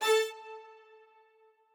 strings8_5.ogg